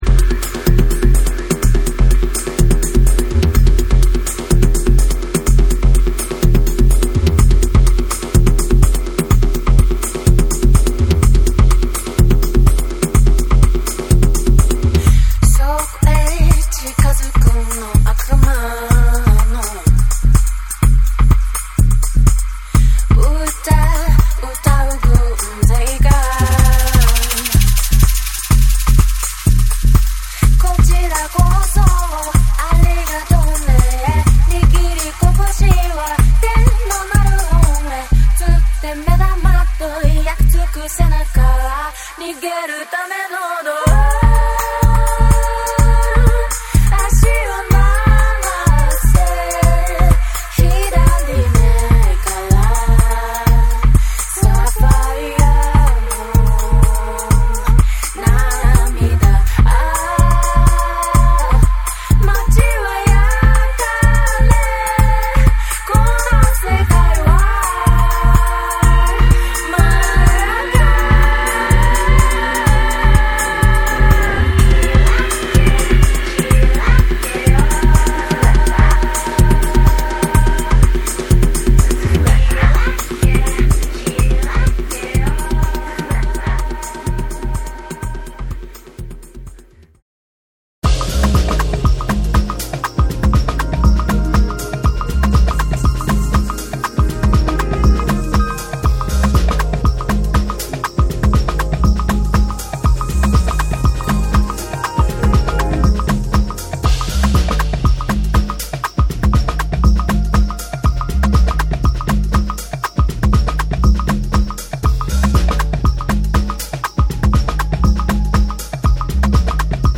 アシッドテクノ、ダブステップなどの影響を感じさせてくれる
JAPANESE / BREAKBEATS